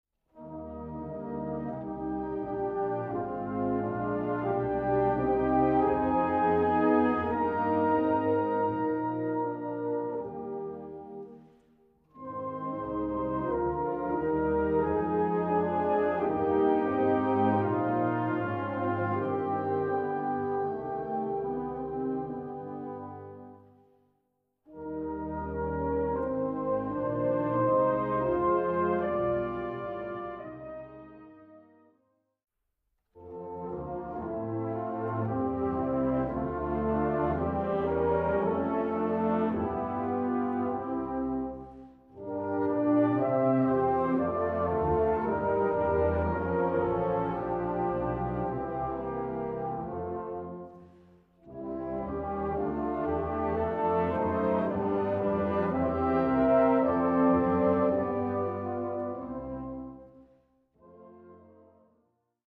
Répertoire pour Harmonie/fanfare - Fanfare